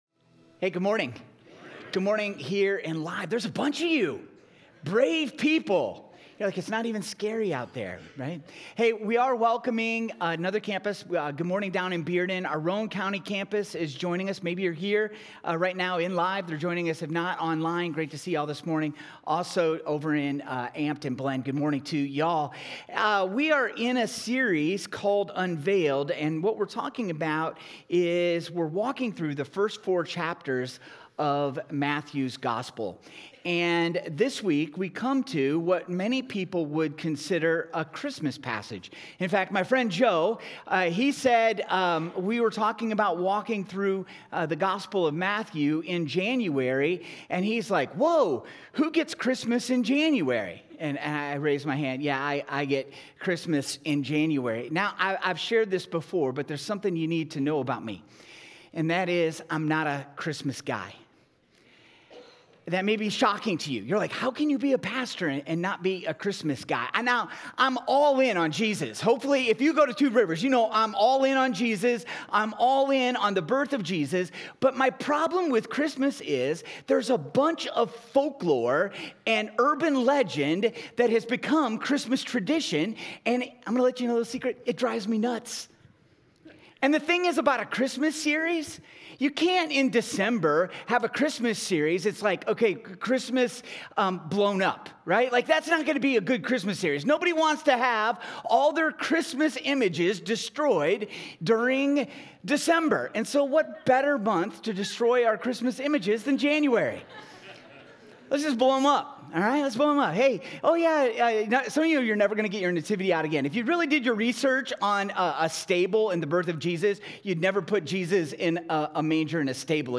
Sermons from Two rivers Church in Knoxville, TN